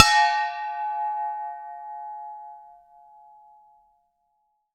bell_med_ringing_01.wav